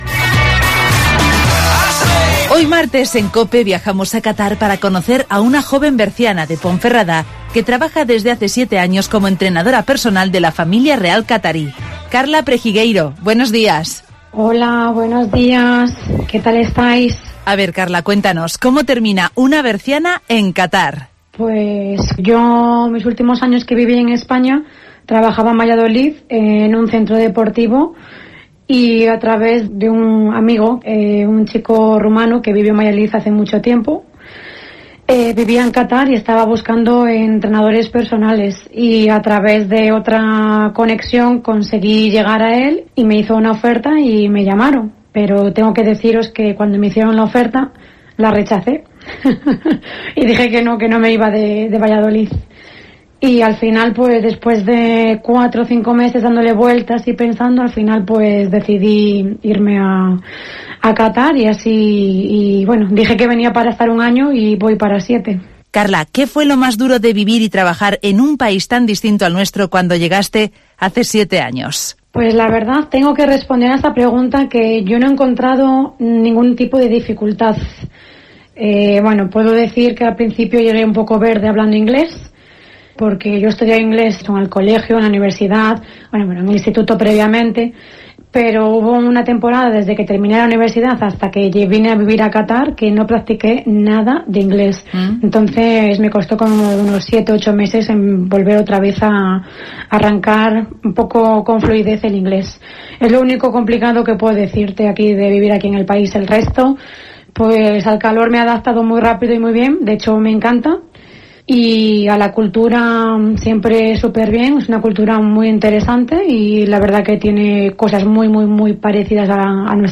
ACTUALIDAD